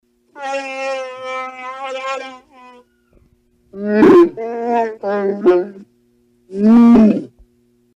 Звуки Чубакки